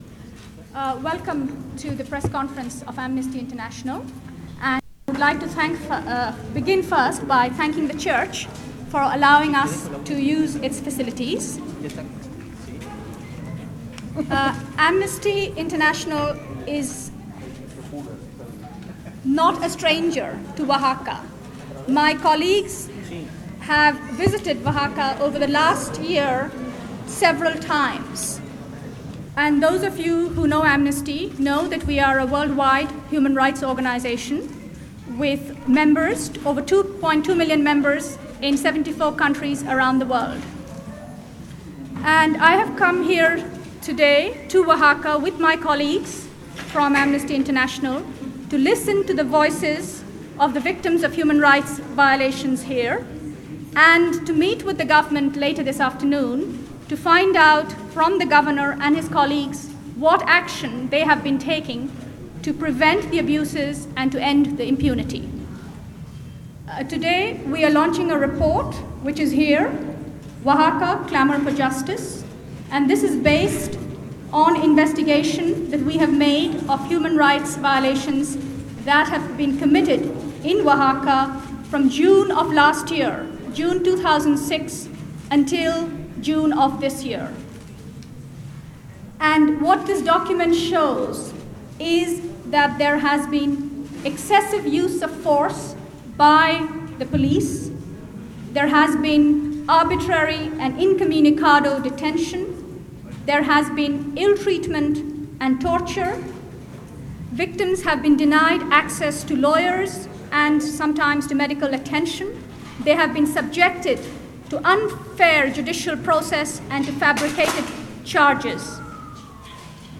((audio)) Amnesty International’s Secretary General in Oaxaca City press conference